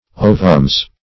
Ovums ([=o]"v[u^]mz).